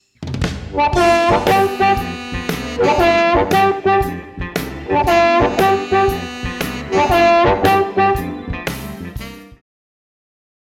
Используя минус, я записал для вас два примера акцентирования.
Фраза, на которой  я это демонстрирую, выглядит следующим образом: -1 -2″ -1 -2 -2